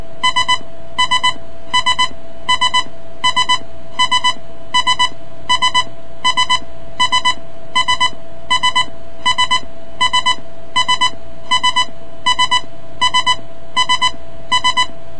* = actual alert sound (for ear training purposes)
US Navy Collision Bell .wav {repeating = veryfast: ding-ding-ding-pause}
US_Navy_Collision_Bell.wav